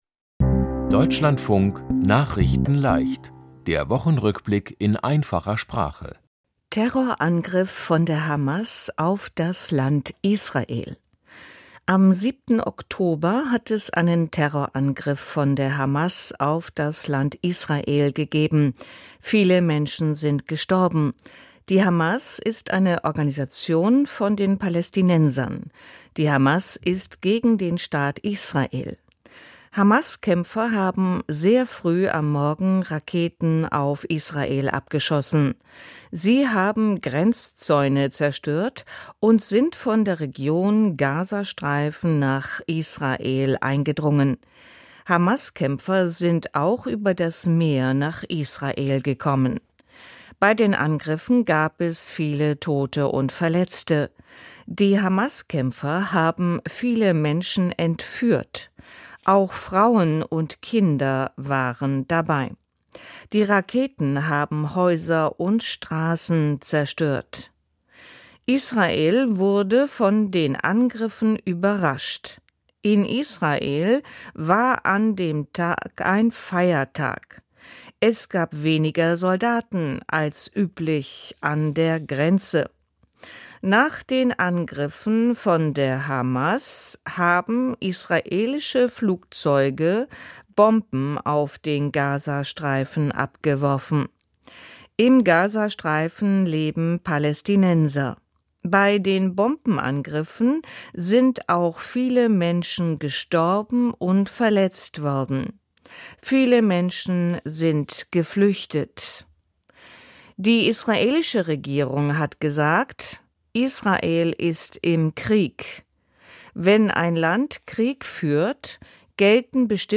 Die Themen diese Woche: Terror-Angriff von der Hamas auf das Land Israel, Deutschland will Hamas-Organisation verbieten, Wahlen in Bayern und Hessen, Viele Tote bei Erd-Beben in Afghanistan und Turn-WM in Belgien. nachrichtenleicht - der Wochenrückblick in einfacher Sprache.